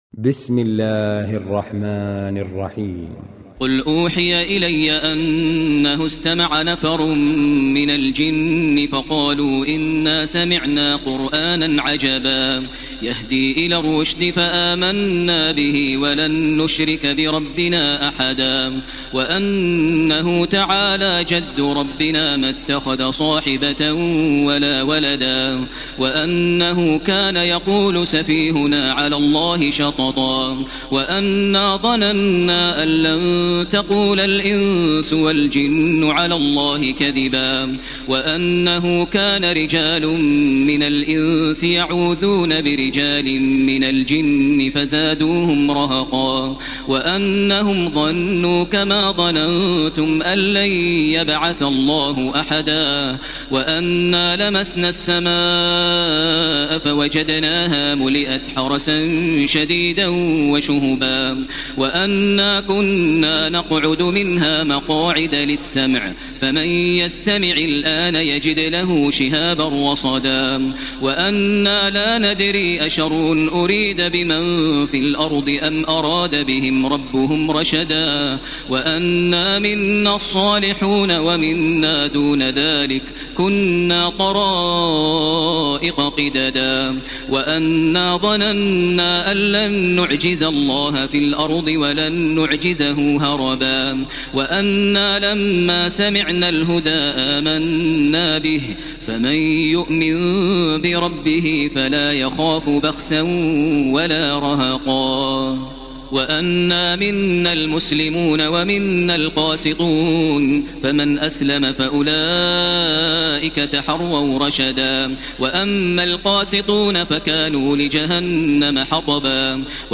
تراويح ليلة 28 رمضان 1433هـ من سورة الجن الى المرسلات Taraweeh 28 st night Ramadan 1433H from Surah Al-Jinn to Al-Mursalaat > تراويح الحرم المكي عام 1433 🕋 > التراويح - تلاوات الحرمين